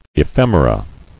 [e·phem·er·a]